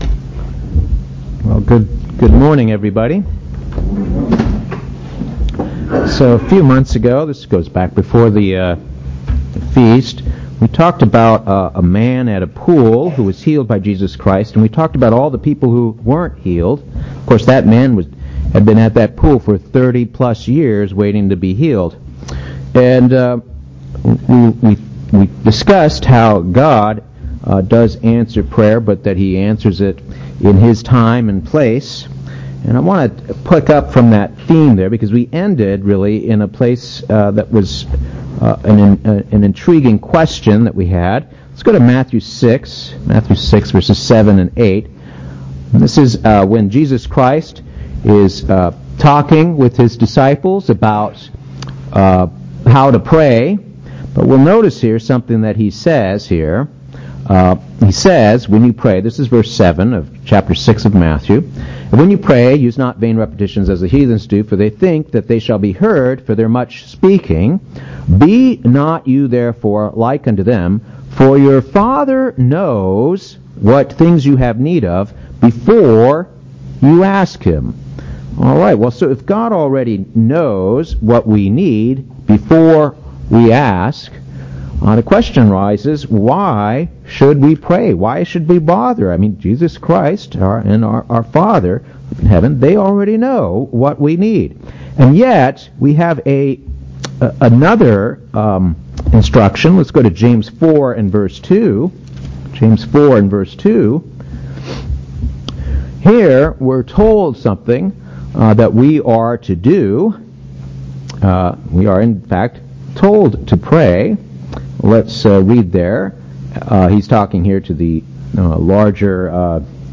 What then is the purpose of our prayers? This split-sermon discusses the value that prayer has in our lives, and how the ancient sacrifices included in the Old Covenant relates to our need to prayer.